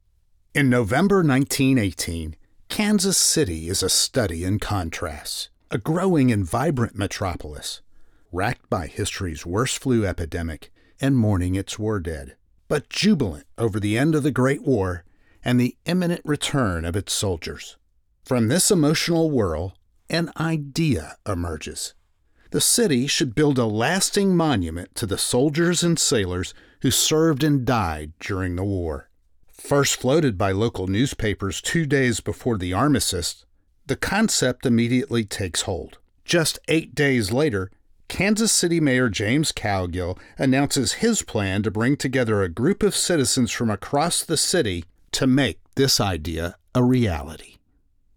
Foreign & British Male Voice Over Artists & Actors
Adult (30-50) | Older Sound (50+)